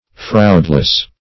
Search Result for " fraudless" : The Collaborative International Dictionary of English v.0.48: Fraudless \Fraud"less\, a. Free from fraud.
fraudless.mp3